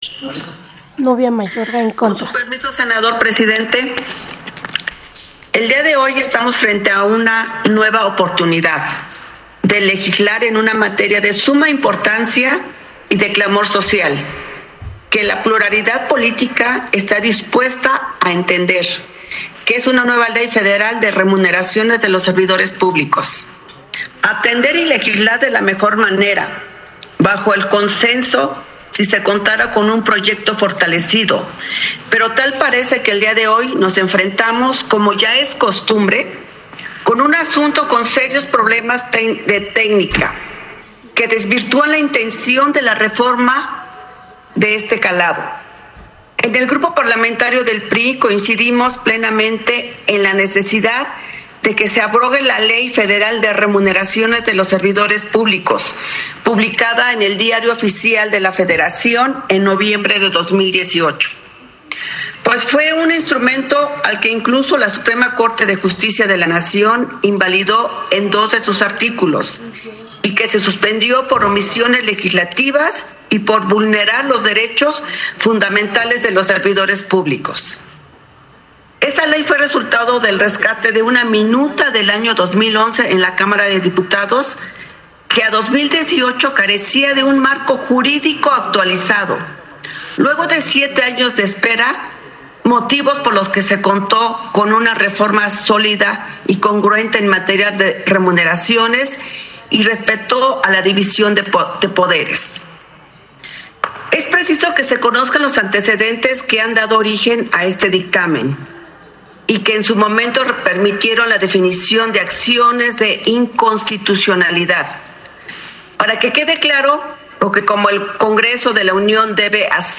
Intervención de la senadora por el PRI, Nuvia Mayorga Delgado, para hablar en contra del proyecto por el que se abroga la Ley Federal de Remuneraciones de los Servidores Públicos
Senado de la República, 27 de abril de 2021